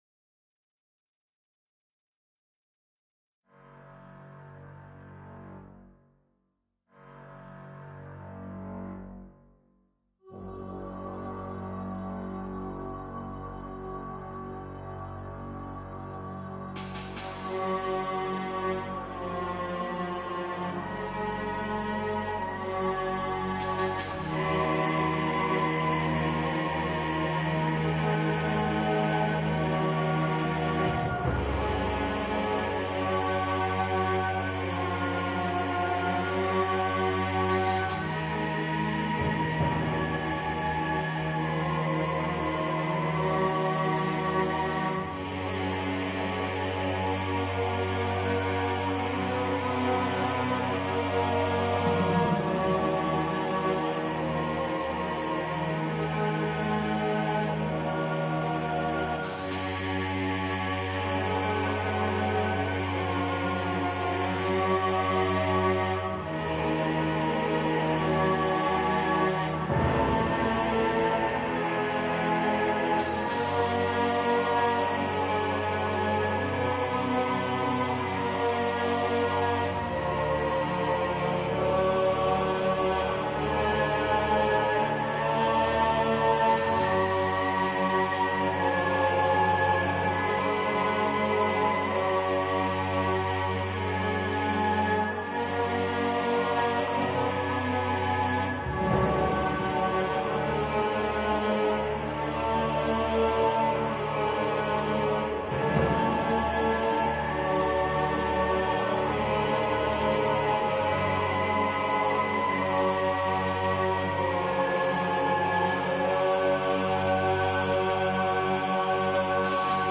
音乐 " Duduk与乐队
Tag: 弦乐 小提琴 大提琴 音乐 得分 电影 乐团 戏剧 电影 中提琴 杜读管 低音 气氛